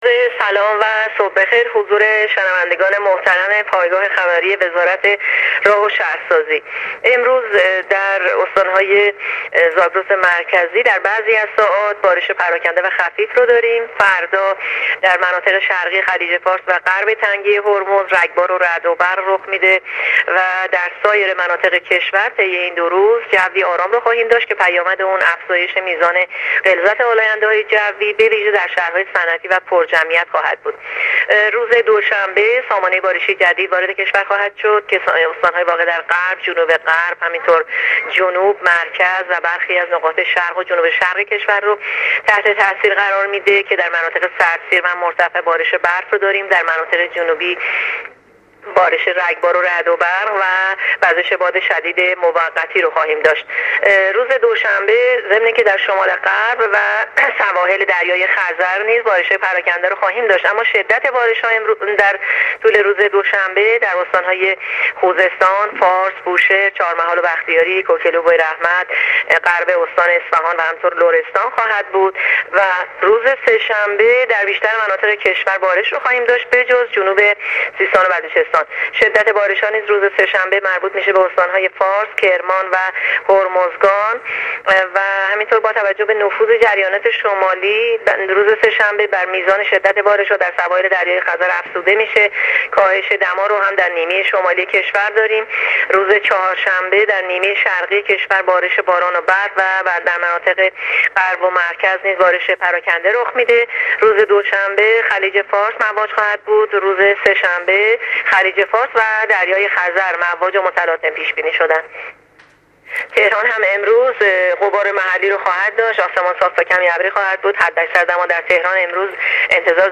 گزارش رادیو اینترنتی از آخرین وضعیت آب و هوای روز شنبه ۲۳ آذرماه: